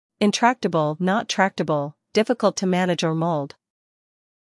英音/ ɪnˈtræktəbl / 美音/ ɪnˈtræktəb(ə)l /